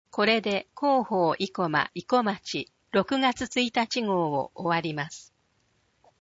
声の広報「いこまち」令和元年6月1日号 | 生駒市公式ホームページ